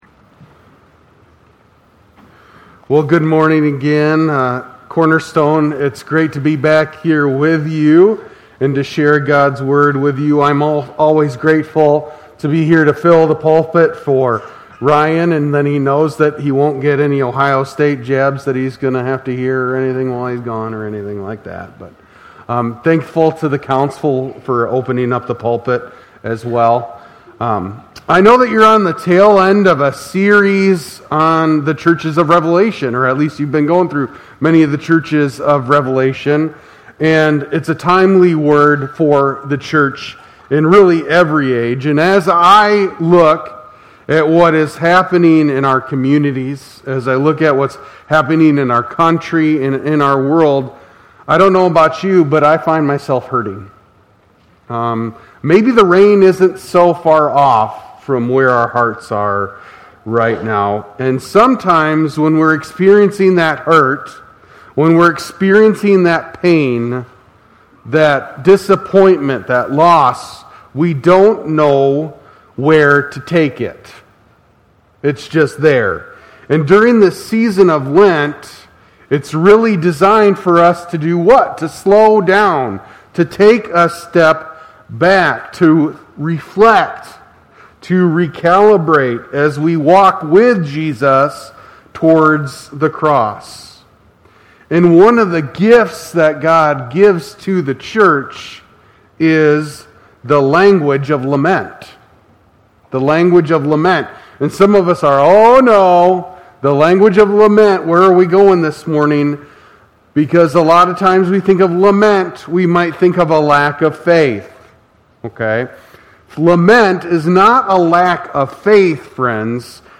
2026 at Cornerstone Church in Pella.